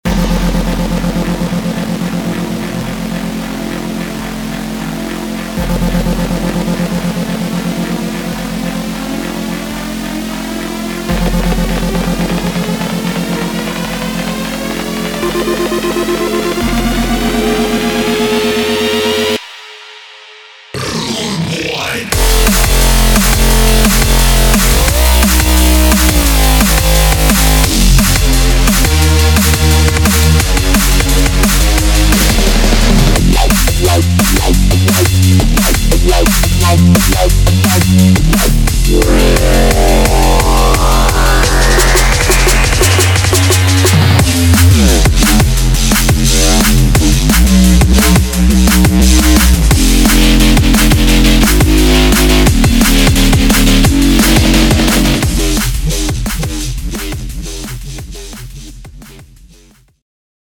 Drum And Bass
3 x Arps, 73 x Bass, 37 Drum, 13 x Lead, 5 x Pad, 18 x FX.